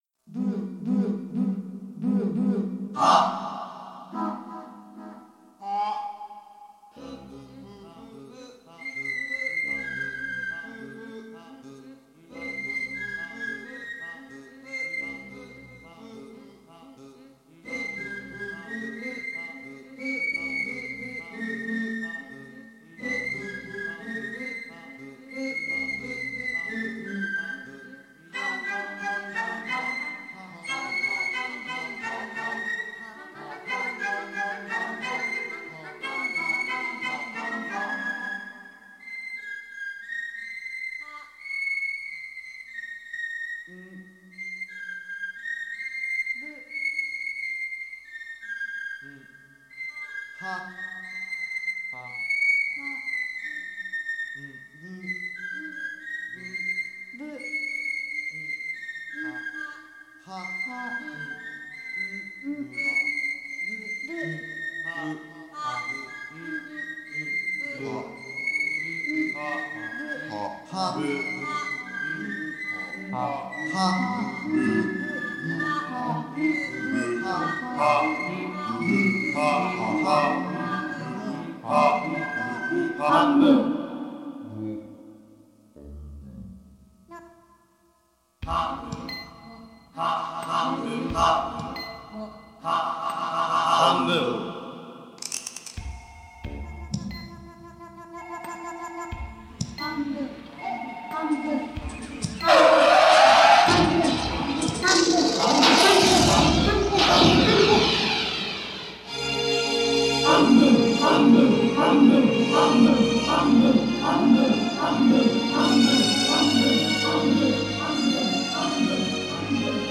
for Electronic music, Video, Laser, Performance and more
なお、残っていた音源がプラネタリウム公演用で、ダイナミクスの幅が広かったものは、若干のコンプ処理をしてあります。
サンプリングシンセサイザーが出始めた時期の作品ですから、ここぞとばかりに使ってあります。